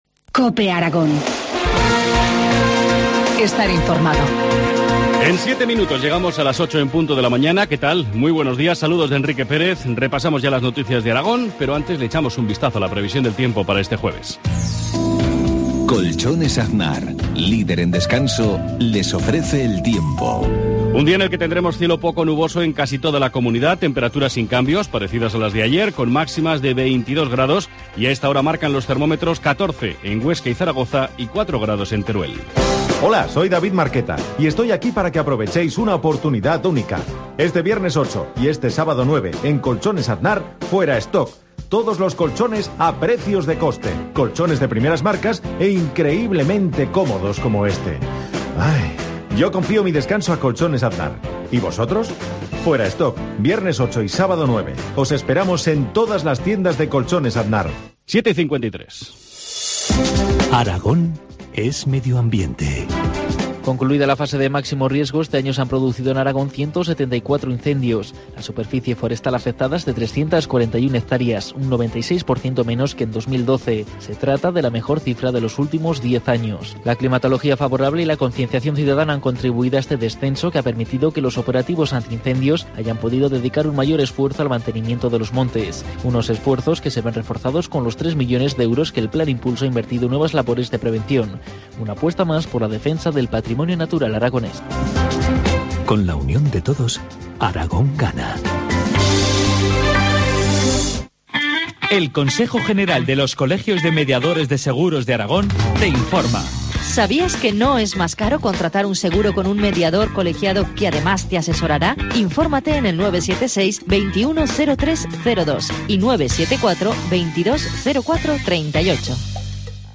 Informativo matinal, jueevs 7 de noviembre, 7.53 horas